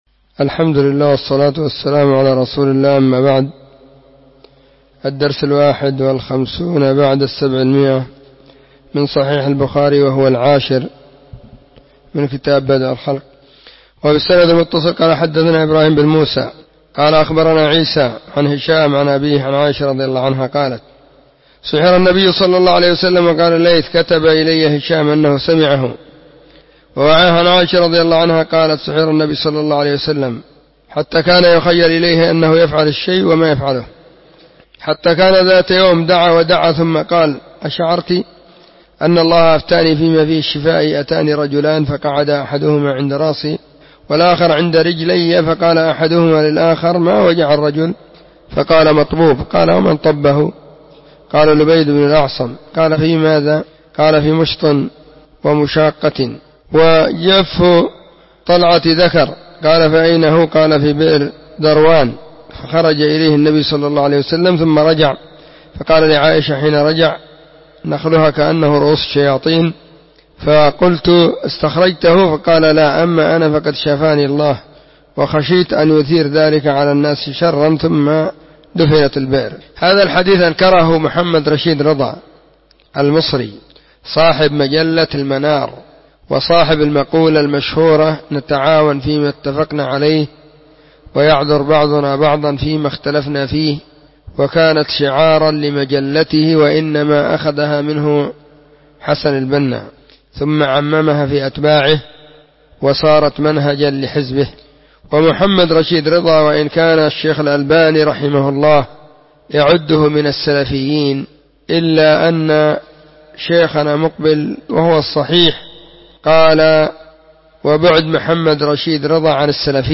🕐 [بين مغرب وعشاء – الدرس الثاني]
كتاب-بدء-الخلق-الدرس-10.mp3